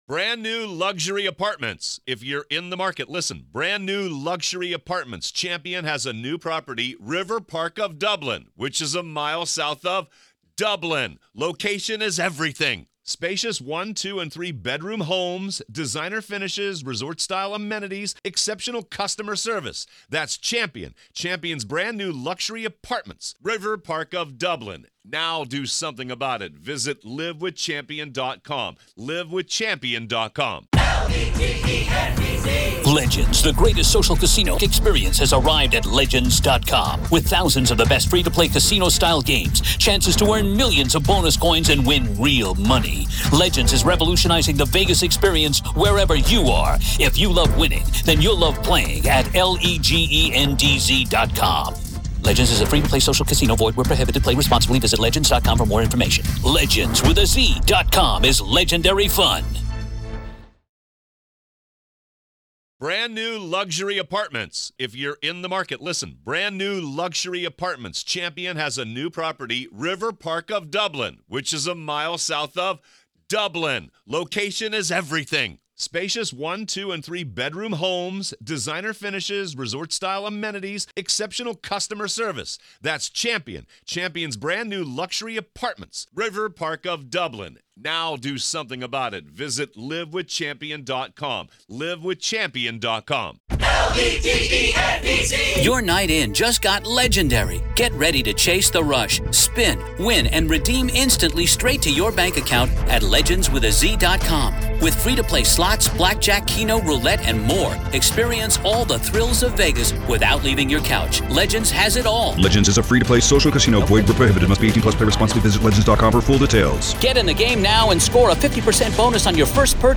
two in-depth conversations